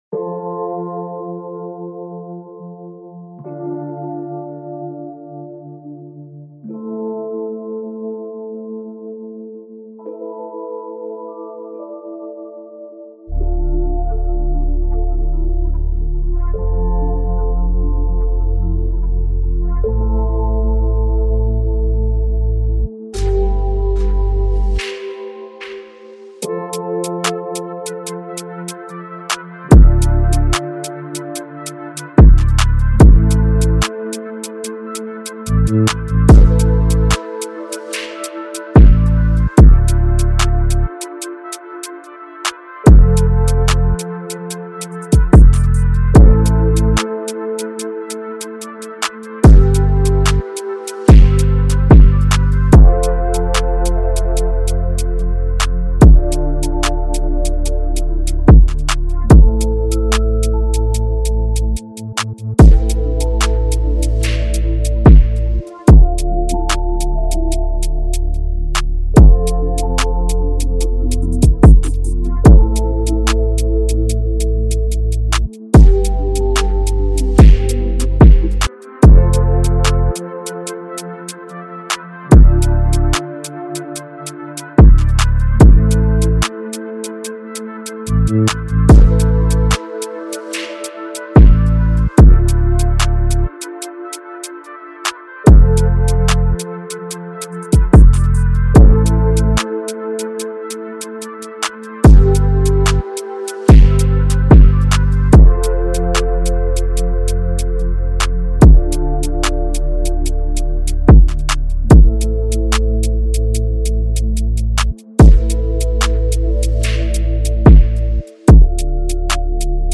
This is the official instrumental
UK Drill Instrumentals